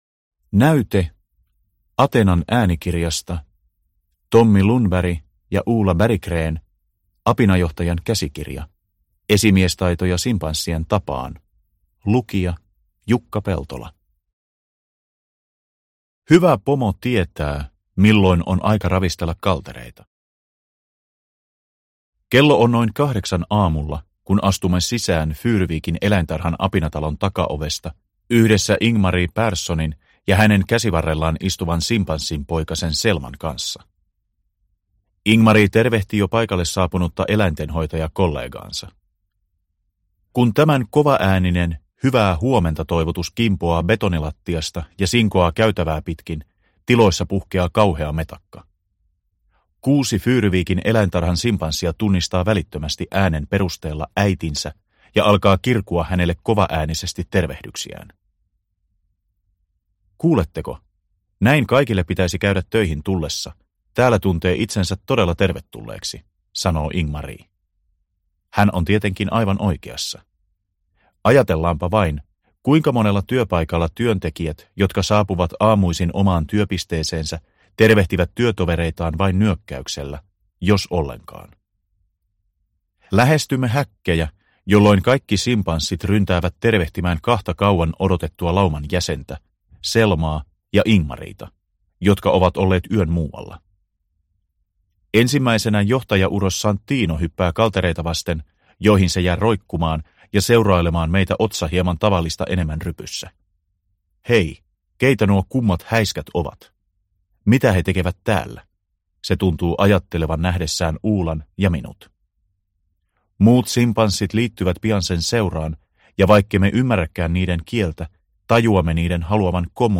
Apinajohtajan käsikirja – Ljudbok – Laddas ner